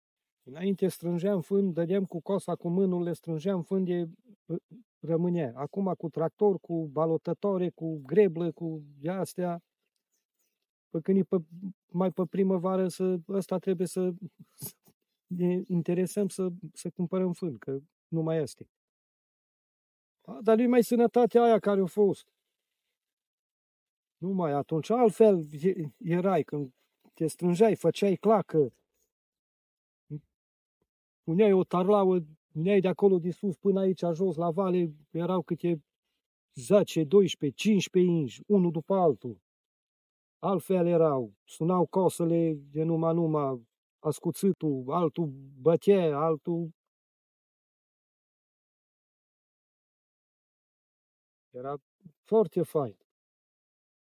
O arhivă de sunete și mirosuri, povești și obiecte din gospodărie sunt comoara Punctului de memorie colectivă care se deschide în 30 martie la Vad, județul Cluj.
La cosit
Cosit-Vad.mp3